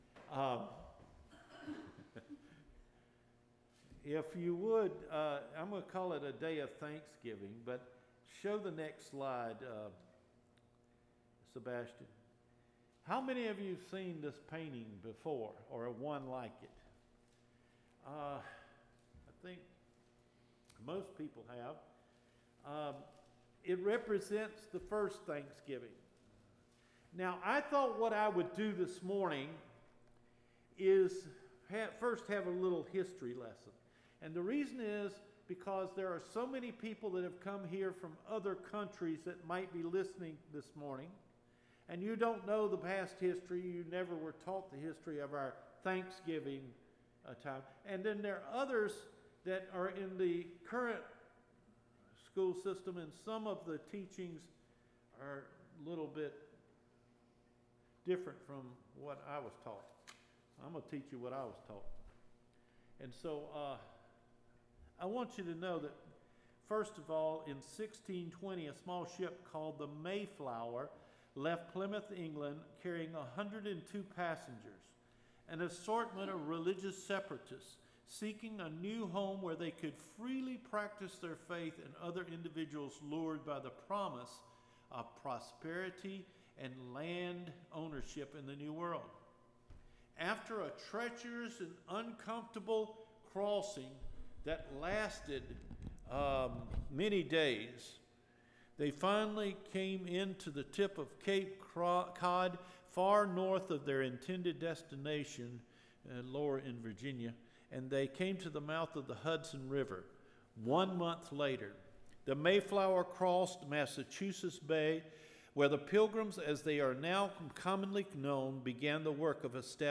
NOVEMBER 22 SERMON – PSALM 100: A DAY FOR THANKSGIVING
Recorded Sermons